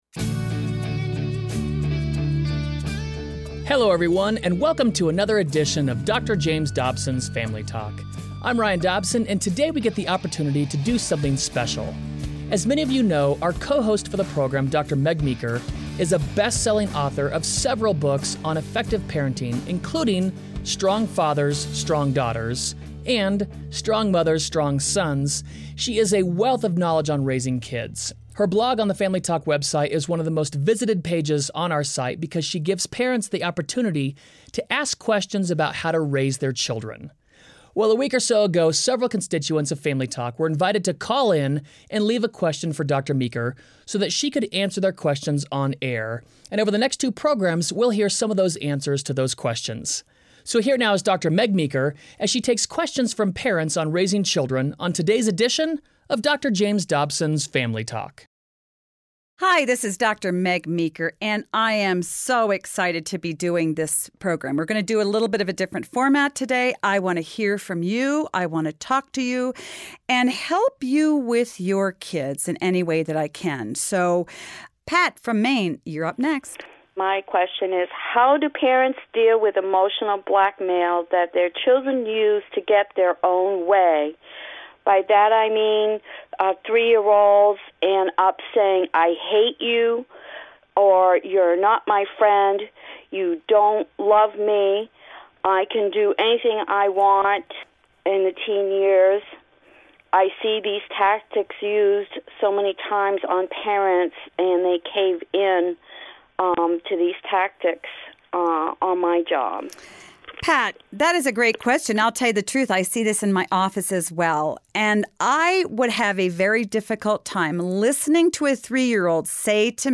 will answer questions about parenting from different callers around the country.